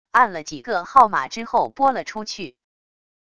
按了几个号码之后拨了出去wav音频